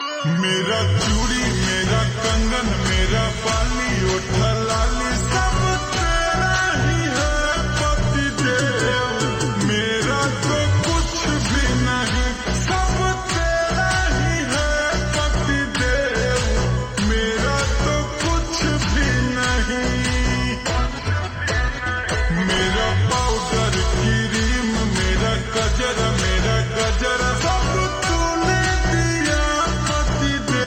• Simple and Lofi sound
• Crisp and clear sound